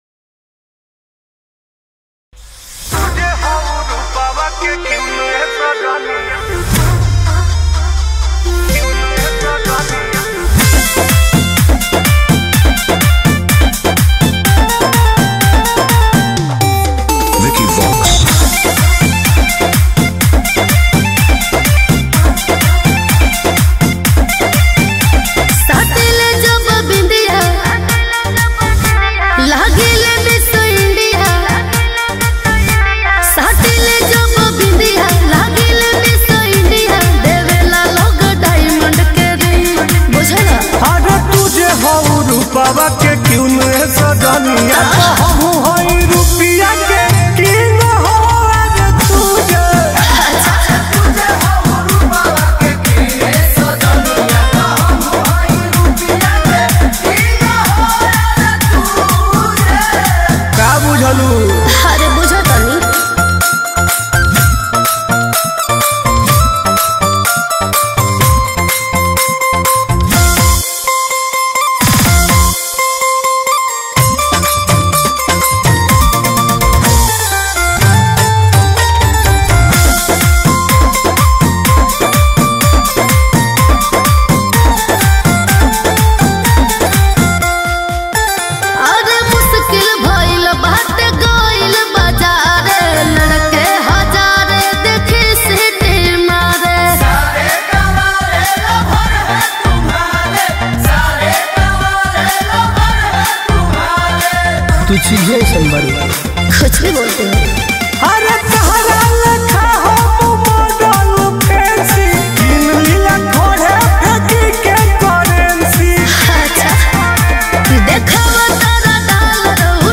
Releted Files Of Bhojpuri Mp3 Song